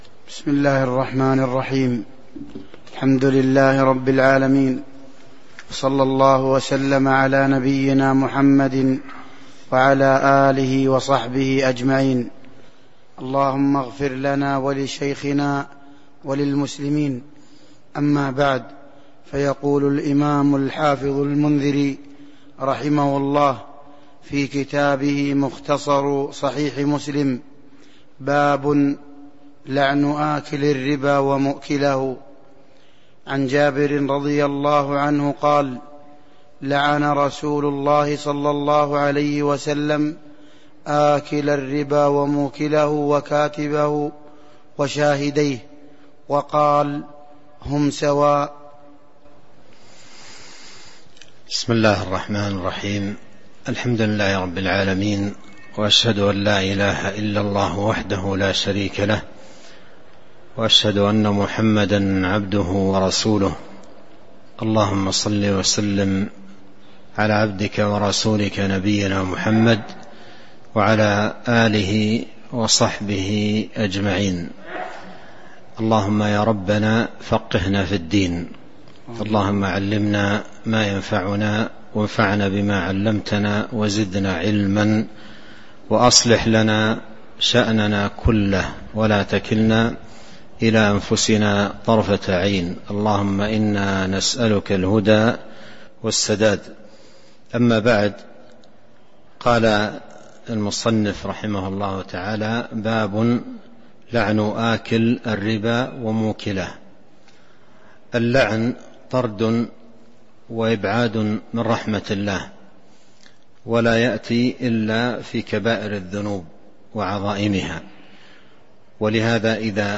تاريخ النشر ٩ صفر ١٤٤٣ هـ المكان: المسجد النبوي الشيخ